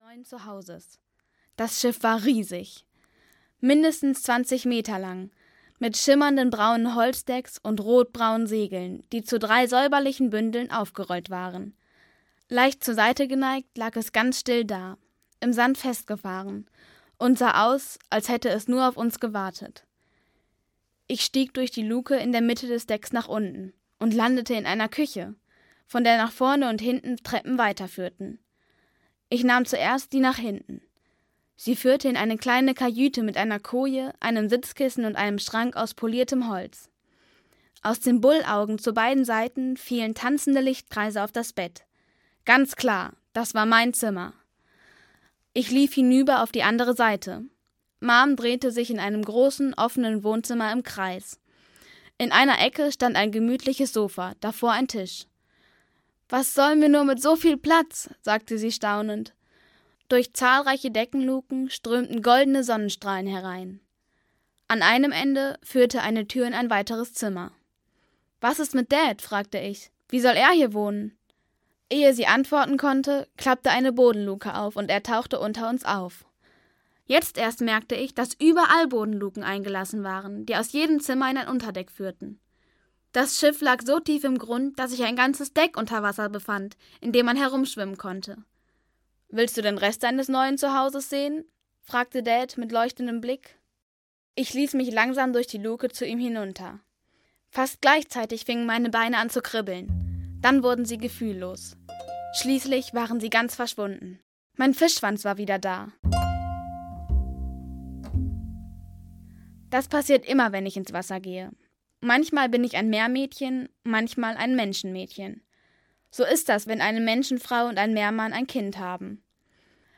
Emilys Abenteuer - Liz Kessler - Hörbuch